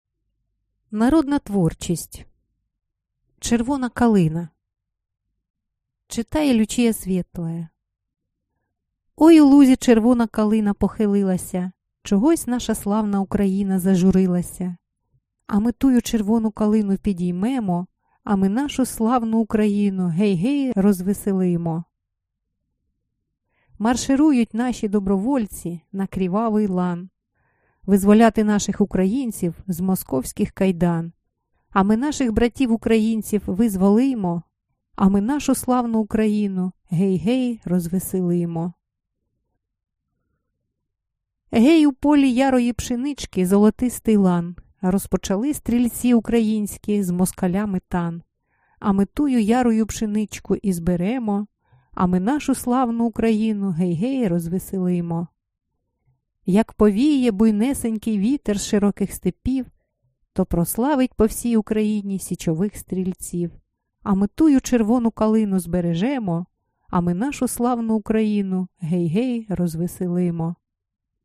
Аудиокнига Стрілецькі пісні | Библиотека аудиокниг